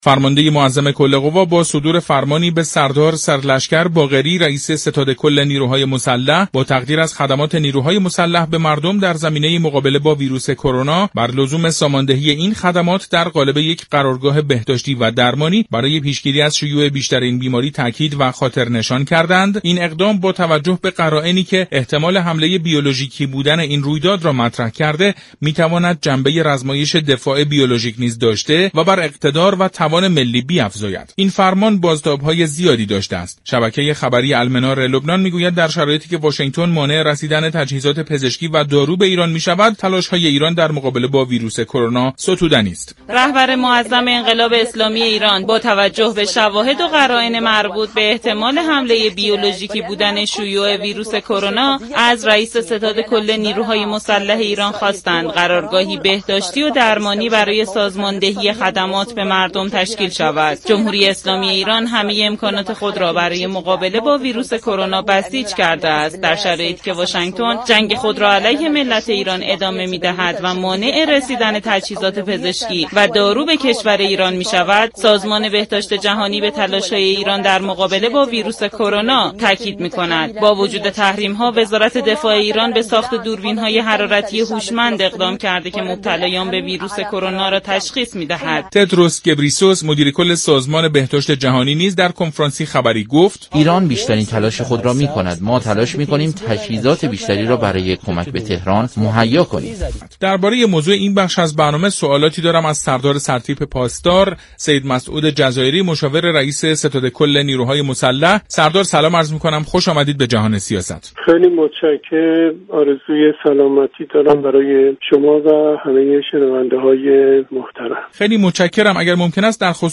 به گزارش شبكه رادیویی ایران، سردار سرتیپ پاسدار سید مسعود جزایری مشاور رییس ستاد كل نیروهای مسلح در برنامه «جهان سیاست» در این باره گفت: ویروس كرونا كه این روزها سلامت مردم را به خطر انداخته است، بعد امنیتی نیز دارد از این رو برای مقابله با این بیماری نیاز است در سطح ملی برای آن برنامه ریزی شود.